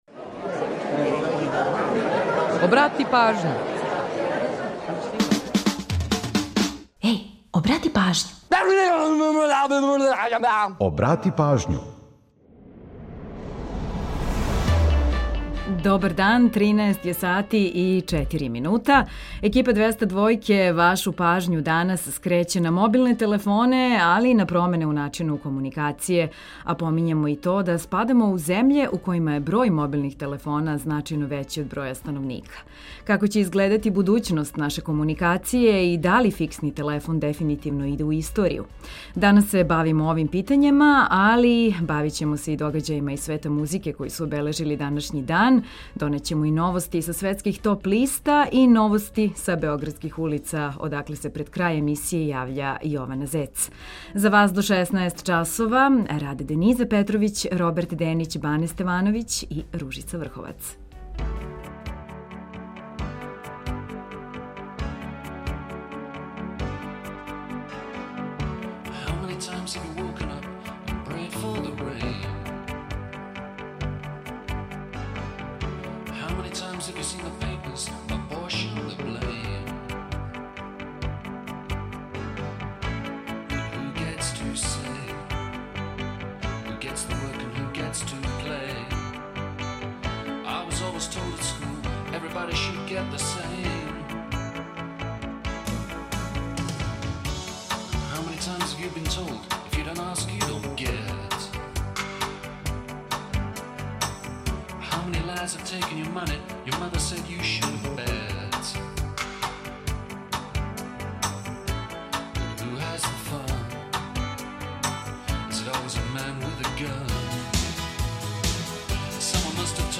То је такође тема емисије, а одговоре дају и наши суграђани. У наставку се бавимо догађајима из света музике који су обележили данашњи дан, доносимо новости са светских топ листа и пола сата само домаће музике и музике из региона.